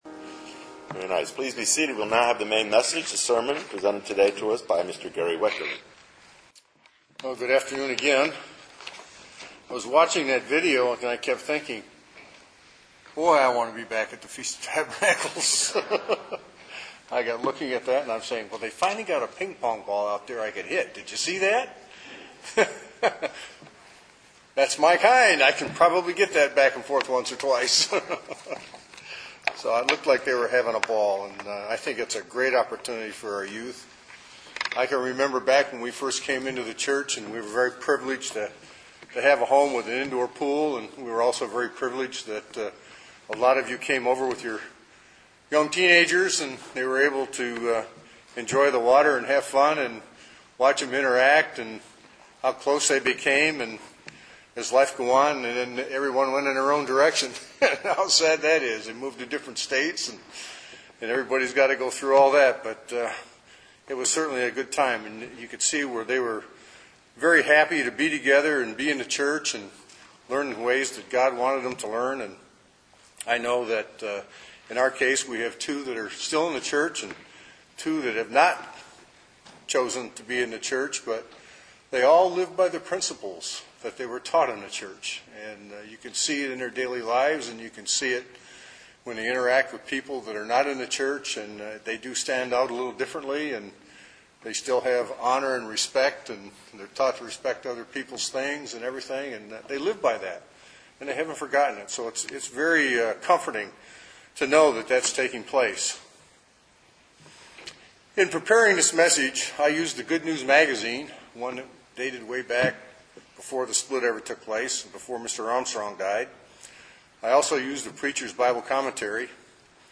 Sermons
Given in Detroit, MI